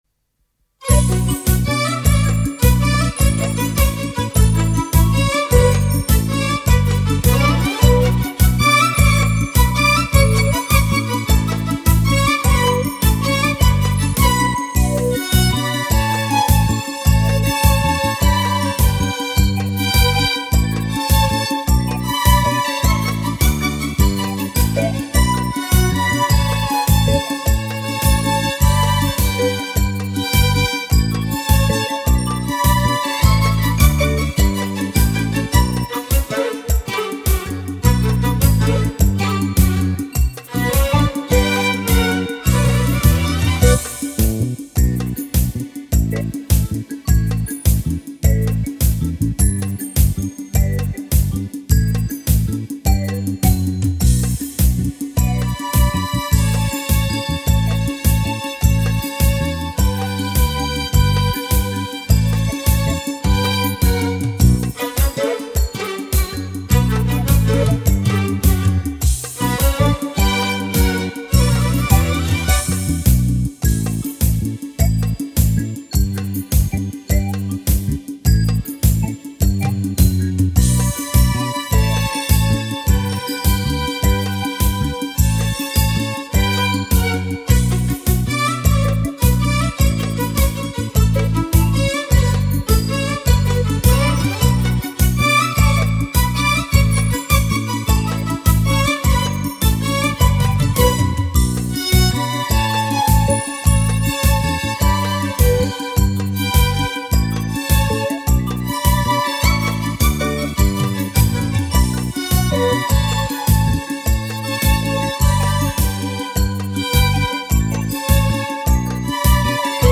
موزیک بی کلام